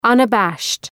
Προφορά
{,ʌnə’bæʃt}